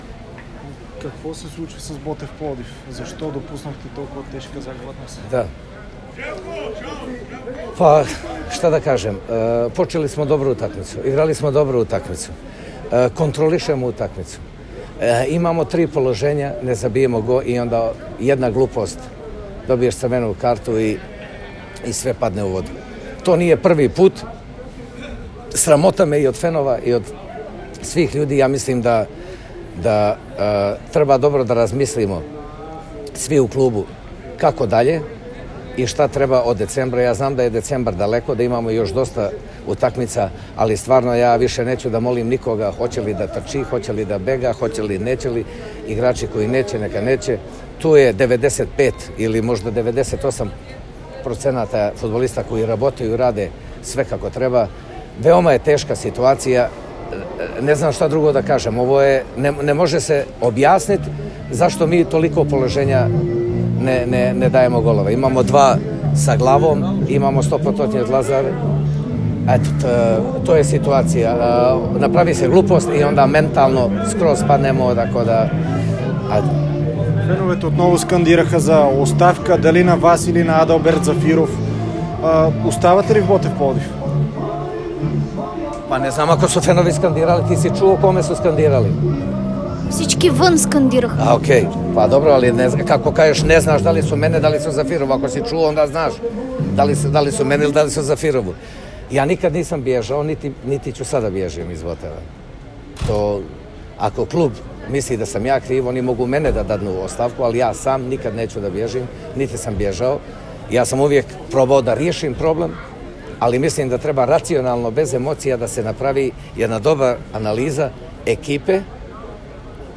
Треньорът на Ботев Пд Желко Петрович заяви след загубата от Ботев Вр с 0:3, че тимът му се нуждае от нови футболисти. Той обаче добави, че не знае защо трябва да се моли на ръководството за нови попълнения.
Чуйте изказването на треньора в звуковия файл.